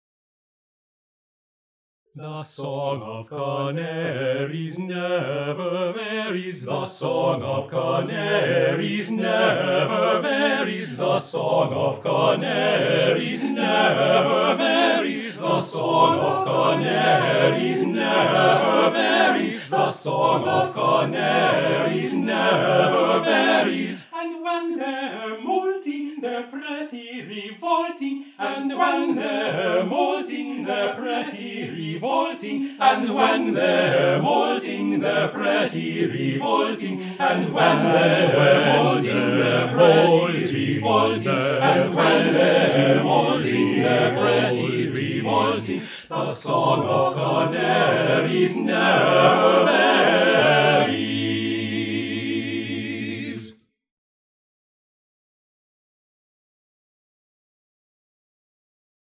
TTBB Chorus a cappella